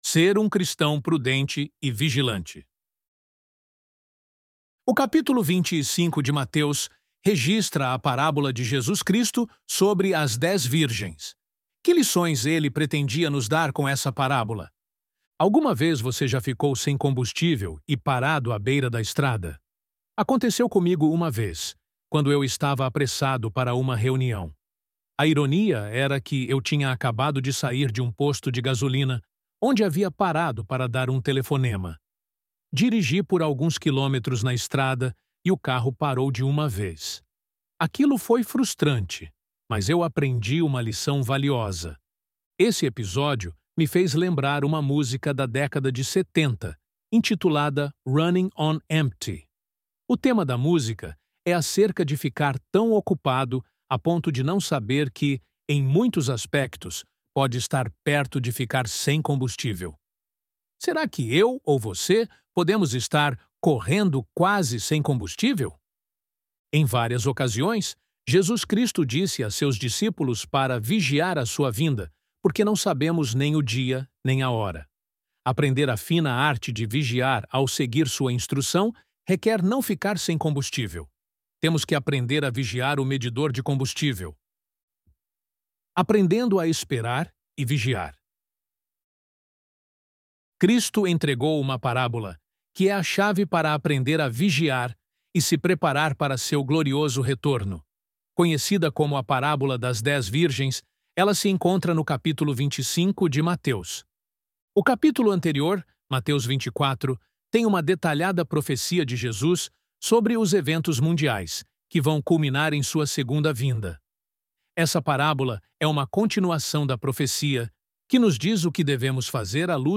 ElevenLabs_Ser_Um_Cristão_Prudente_e_Vigilante.mp3